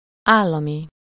Ääntäminen
IPA : /ˈsteɪt/